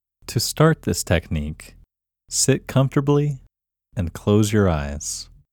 QUIETNESS Male English 1
The-Quietness-Technique-Male-English-1.mp3